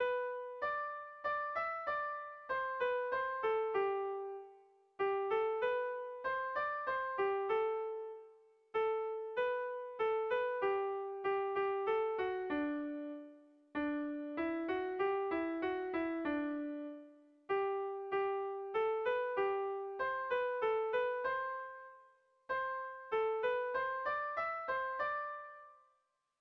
Kontakizunezkoa
Oñati < Debagoiena < Gipuzkoa < Euskal Herria
Lauko handia (hg) / Bi puntuko handia (ip)
ABD